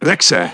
synthetic-wakewords
ovos-tts-plugin-deepponies_Sniper_en.wav